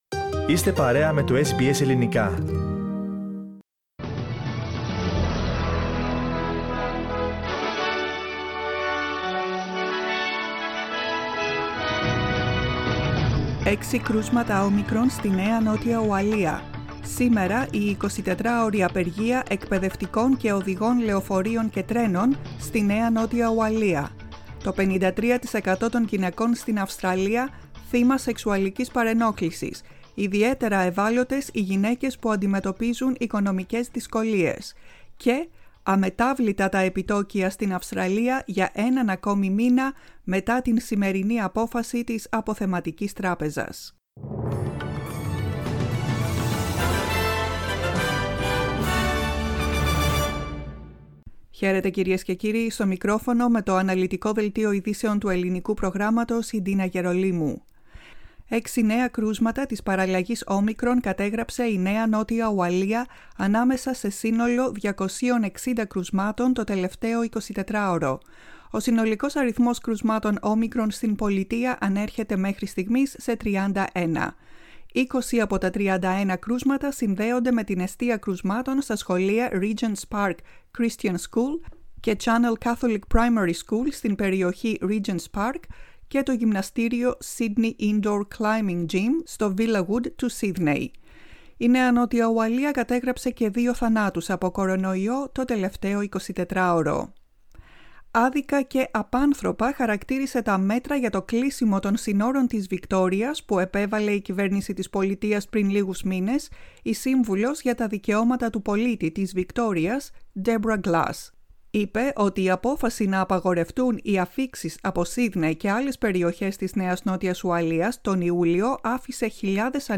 Το κεντρικό δελτίο ειδήσεων του Ελληνικού Προγράμματος.